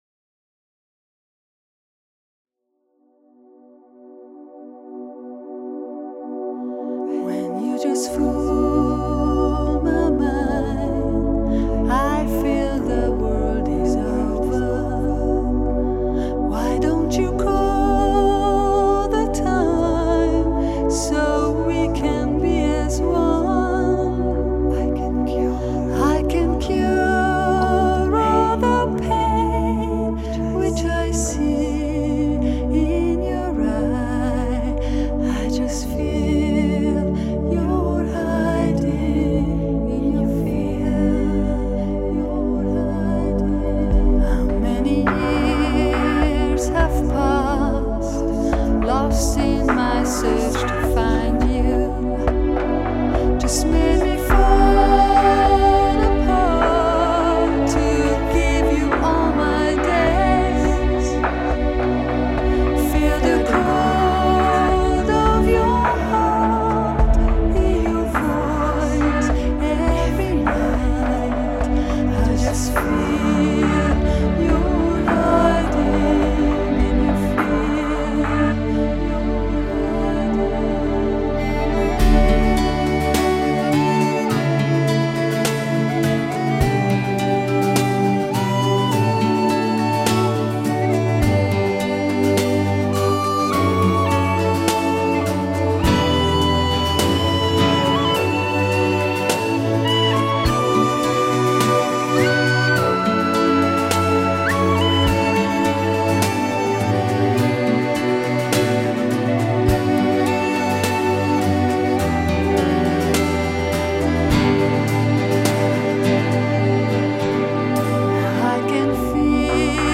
Ez a CD a régiek hangulatától eltérően könnyed popzene lesz.